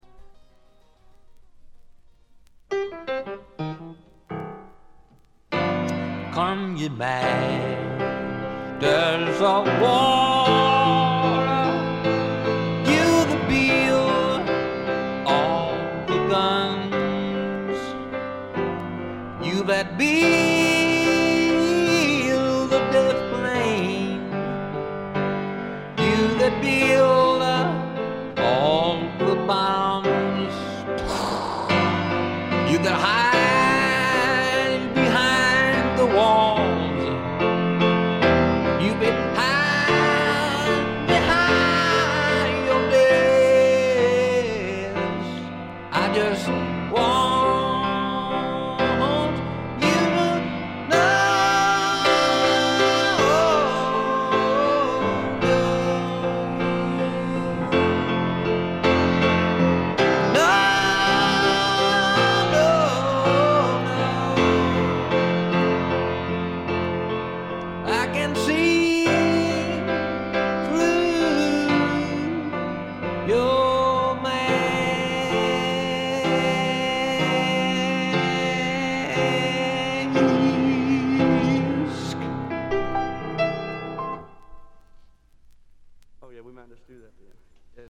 微細なチリプチ程度。
試聴曲は現品からの取り込み音源です。